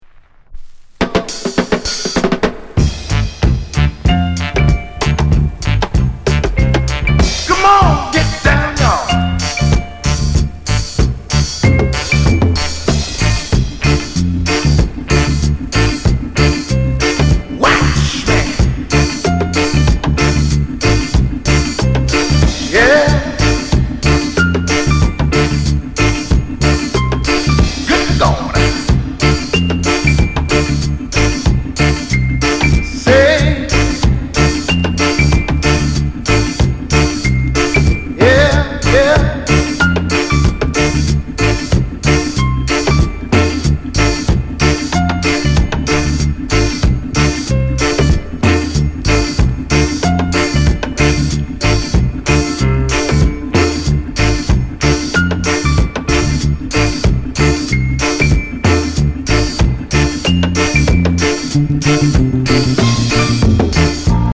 VG+ Coment FUNKY REGGAE